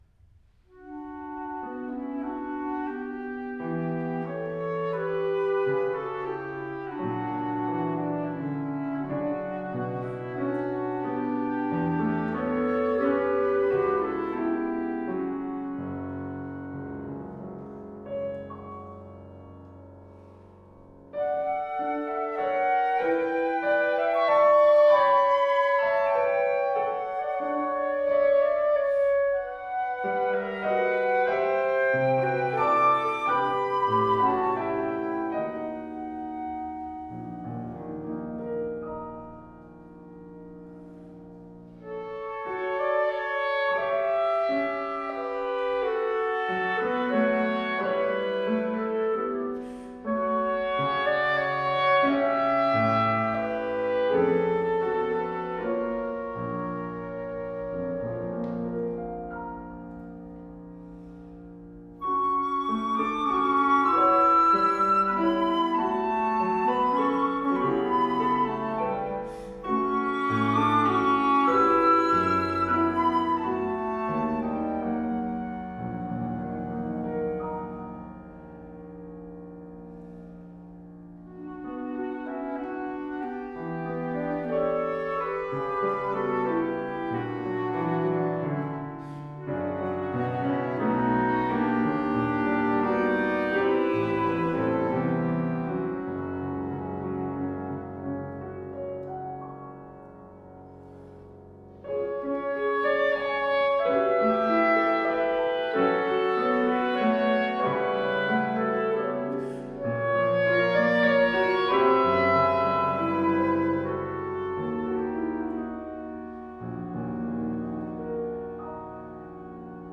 Absolventsky_koncert